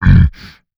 MONSTER_Grunt_Breath_01_mono.wav